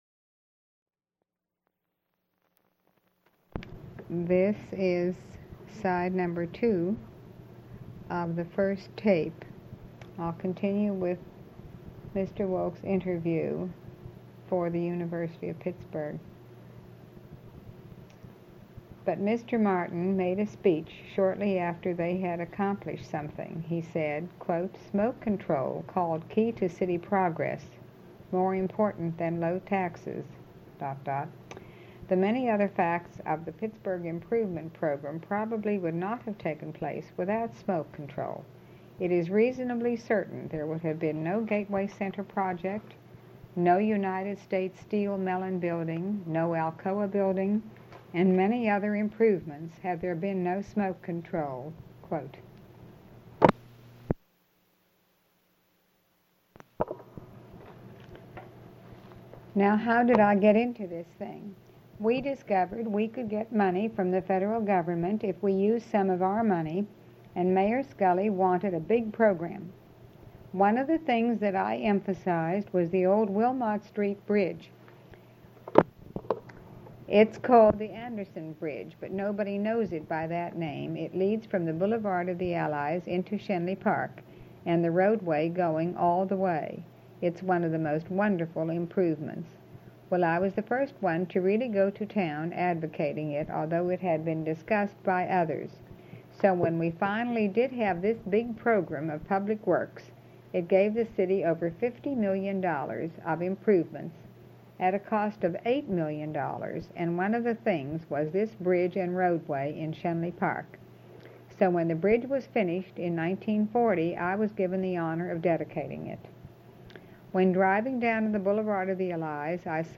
his interview with the University of Pittsburgh concerning Pittsburgh's renaissance